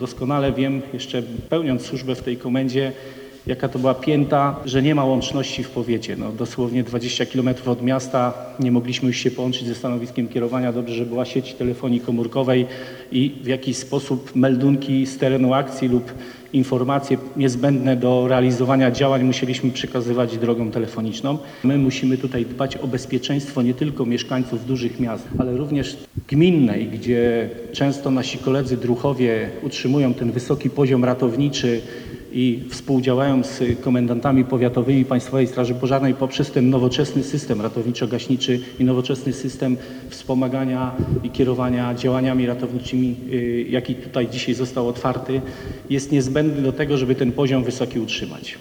Dziś w Komendzie Powiatowej Państwowej Straży Pożarnej w Stargardzie nastąpiło uroczyste otwarcie zmodernizowanego Stanowiska Kierowania.